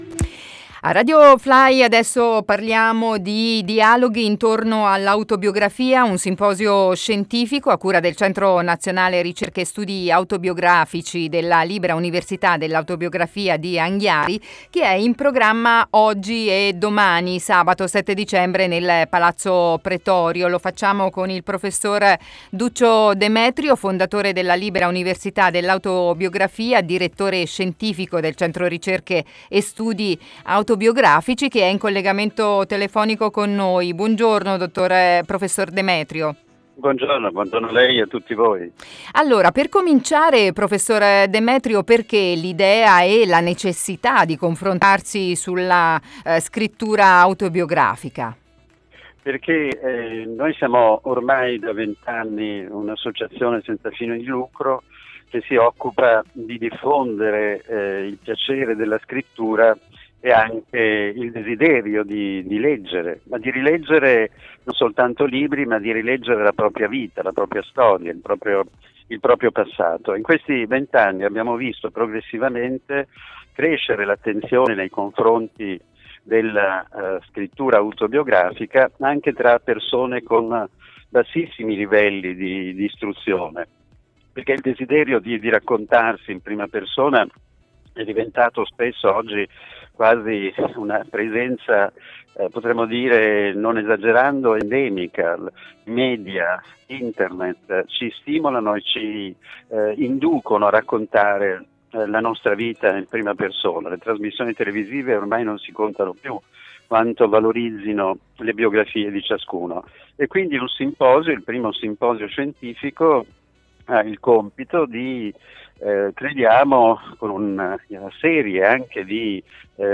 in collegamento telefonico